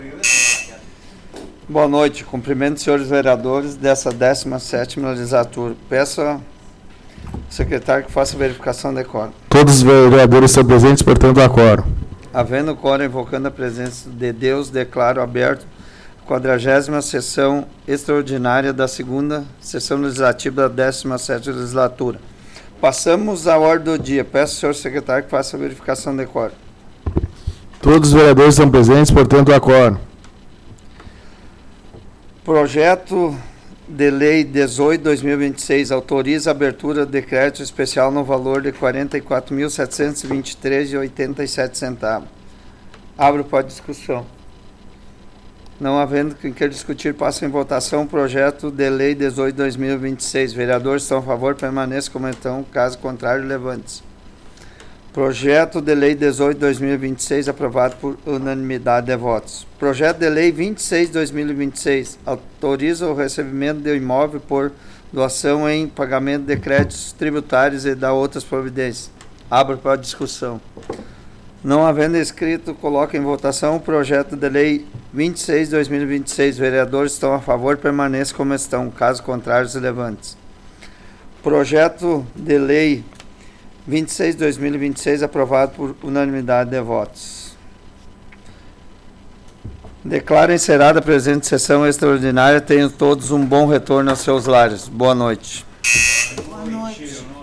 Áudio da 40ª Sessão Plenária Extraordinária da 17ª Legislatura, de 16 de março de 2026